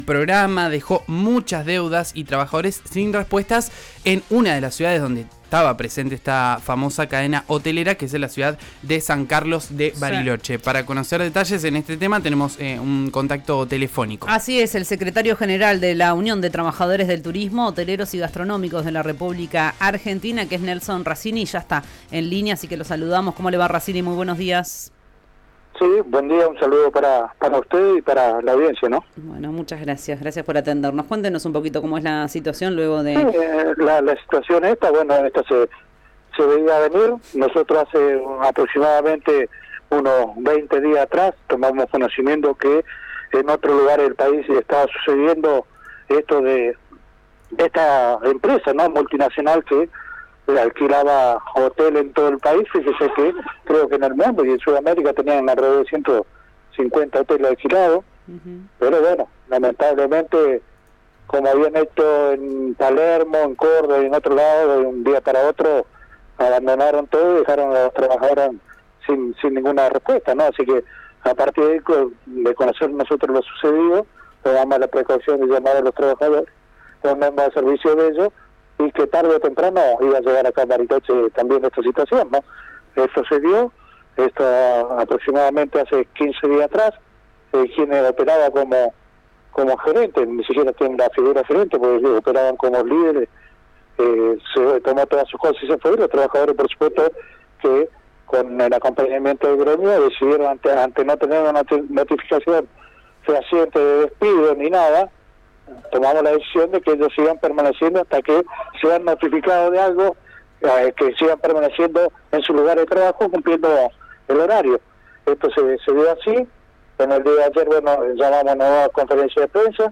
analizó lo sucedido en Bariloche en el aire de RÍO NEGRO RADIO: